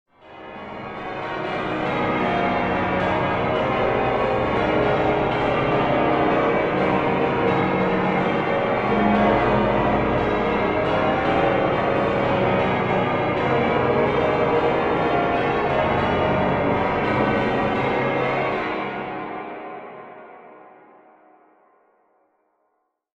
Church Bells, Distant, B